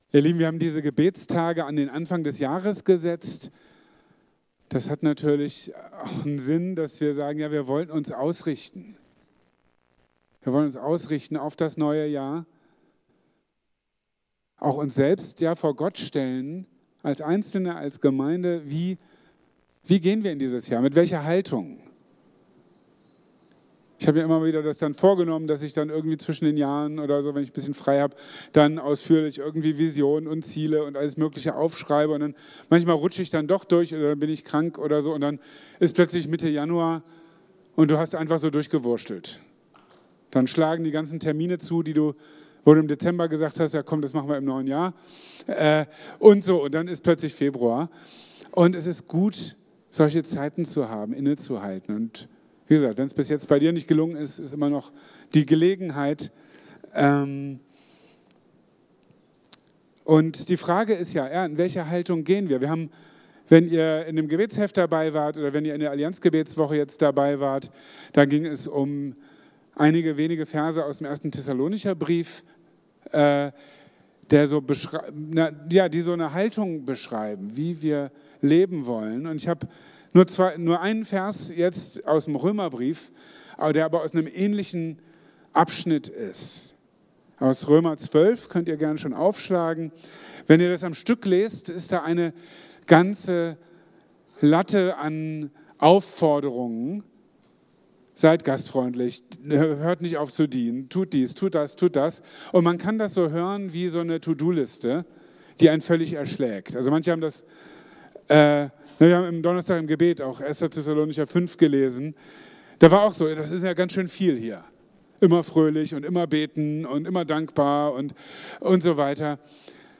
in seiner Predigt vom 19. Januar 2025.